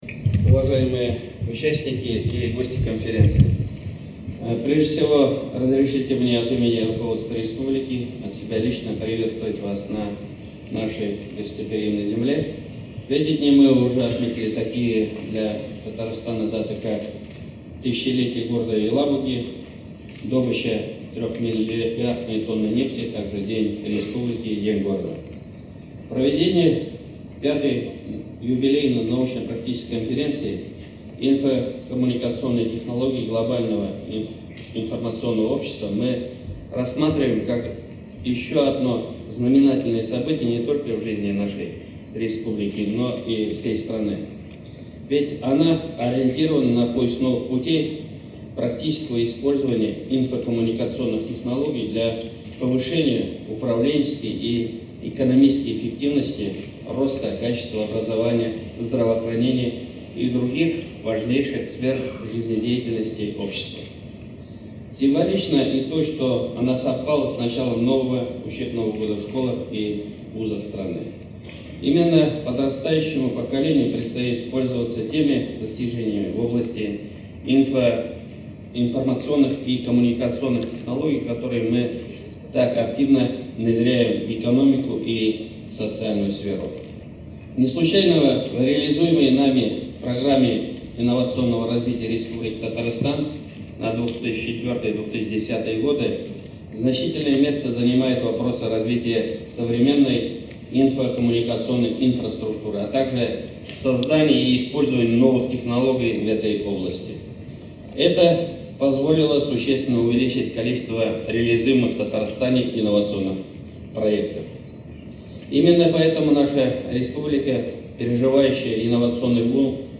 Аудиорепортаж